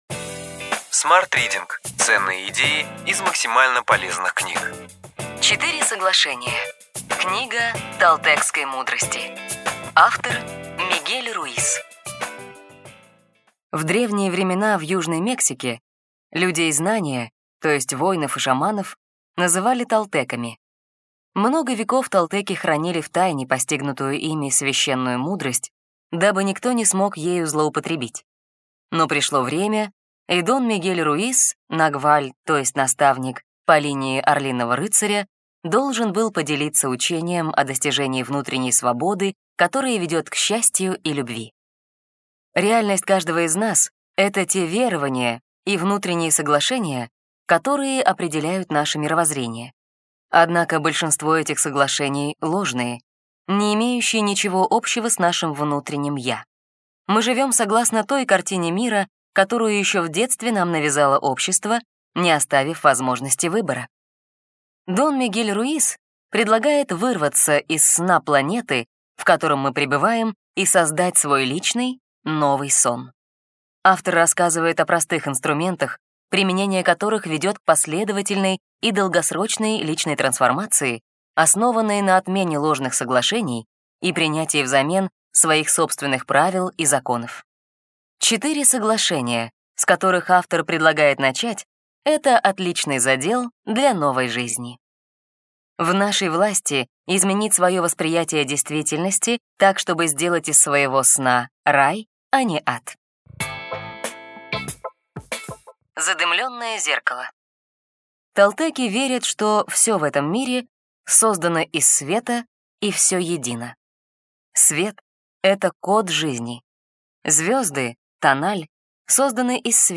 Аудиокнига Ключевые идеи книги: Четыре соглашения.